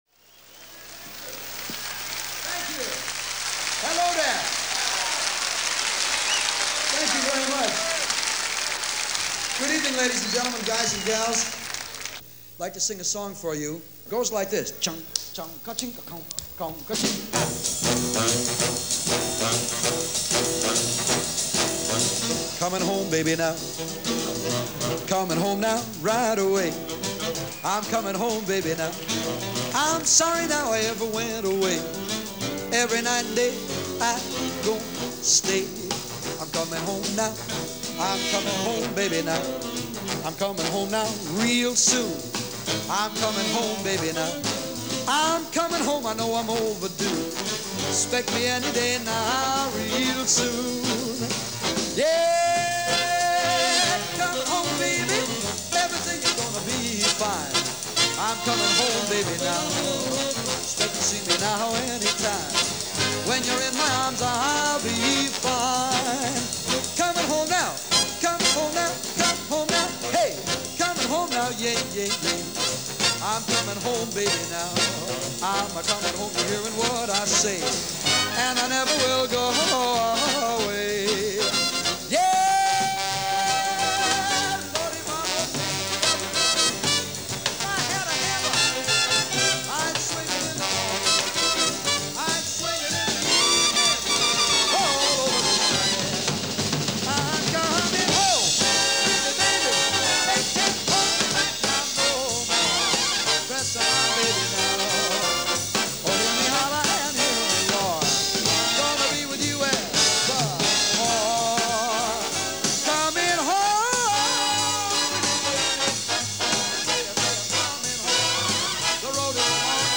the warm, luxuriant voice